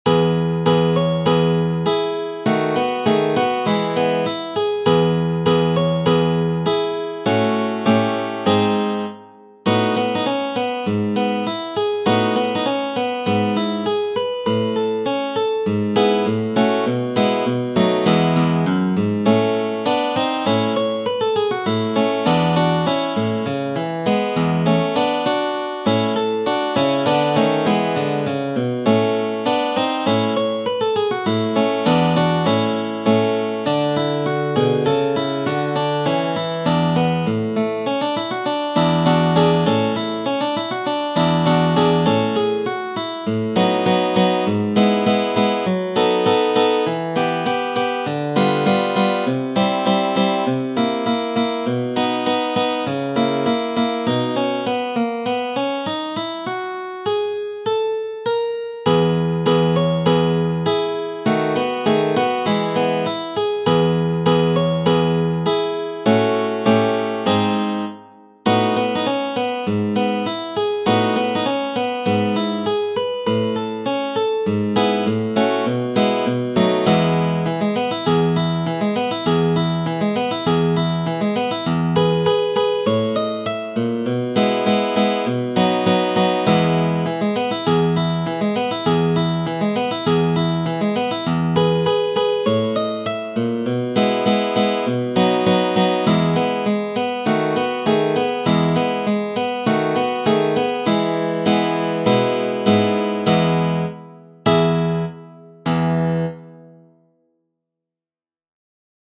op 250 n° 1 – Allegretto in Mi[tab][-♫-]